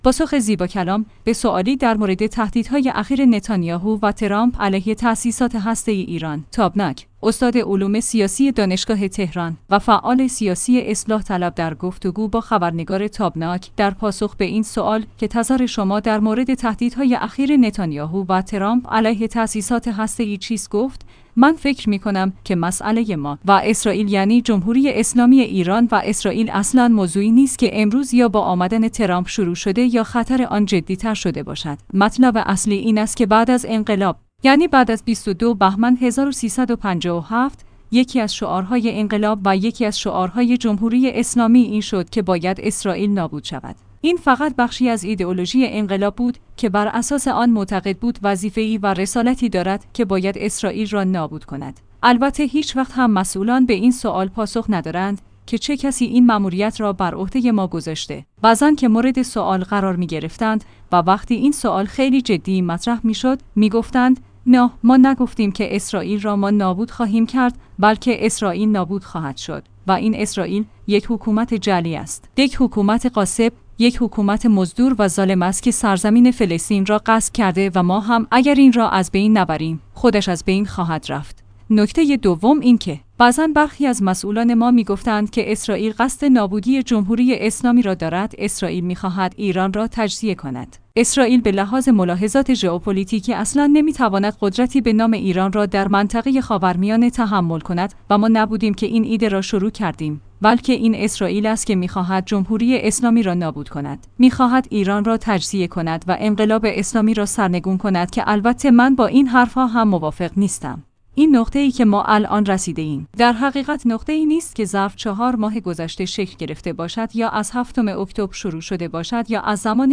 تابناک/ استاد علوم سیاسی دانشگاه تهران و فعال سیاسی اصلاح طلب در گفتگو با خبرنگار تابناک در پاسخ به این سوال که تظر شما در مورد تهدید‌های اخیر نتانیاهو و ترامپ علیه تاسیسات هسته ای چیست گفت : من فکر می‌کنم که مسئله‌ی ما و اسرائیل — یعنی جمهوری اسلامی ایران و اسرائیل — اصلاً موضوعی نیست که امروز یا